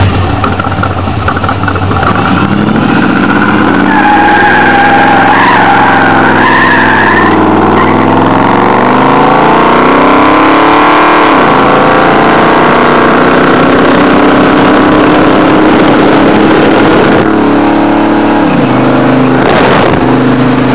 These are with the new 2 1/2" Turbo Tubes.
Hard acceleration with recorder held outside the cab window  238